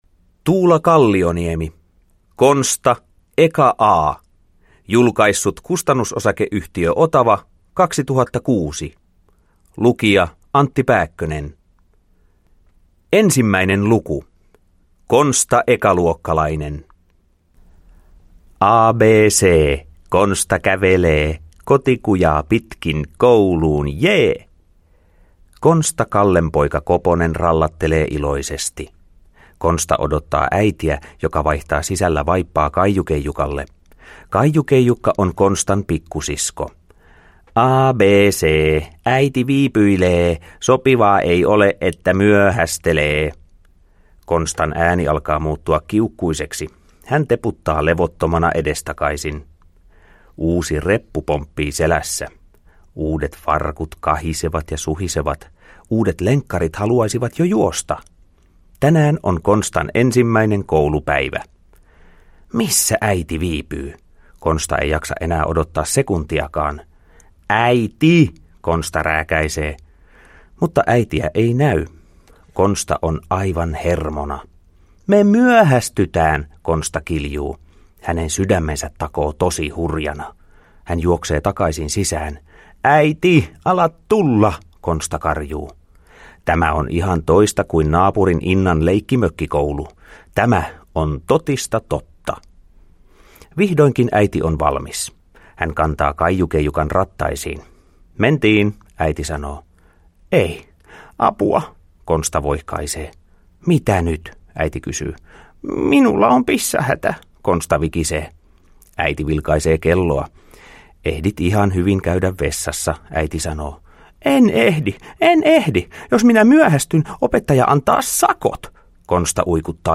Konsta, eka A – Ljudbok – Laddas ner
Uppläsare: Antti Pääkkönen